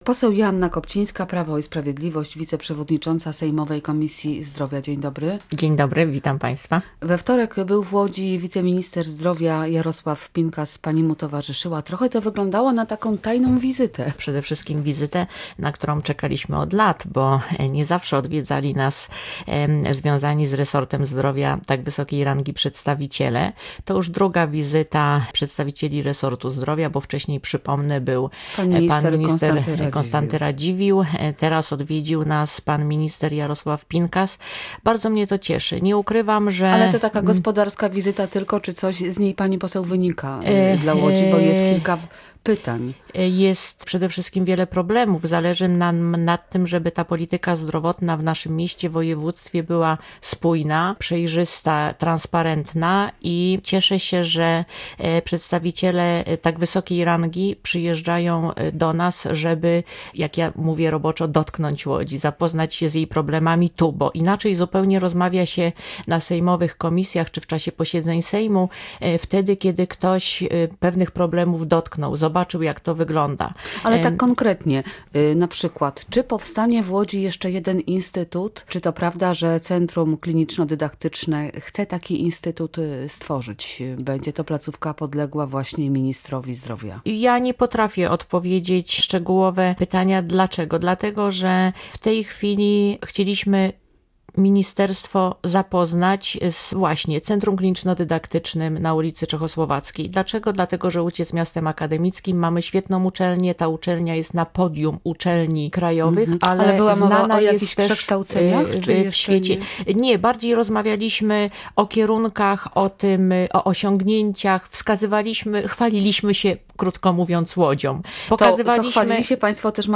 Od września rusza rządowy program darmowych leków dla seniorów. Jak mówiła w Radiu Łódź wiceprzewodnicząca sejmowej komisji zdrowia, poseł PiS, Joanna Kopcińska, za kilka tygodni powinna być już gotowa lista bezpłatnych medykamentów dla osób powyżej 75 roku życia.